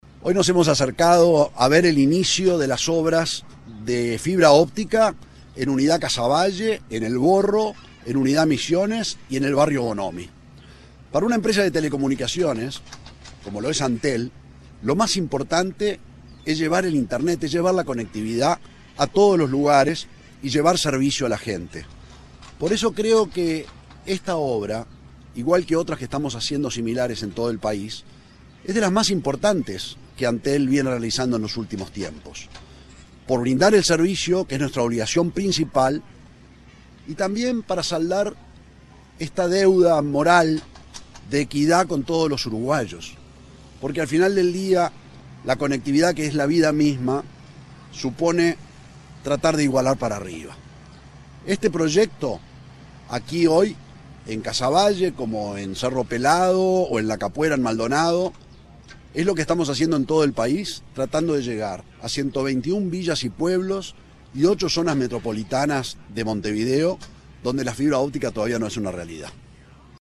Declaraciones del presidente de Antel, Gabriel Gurméndez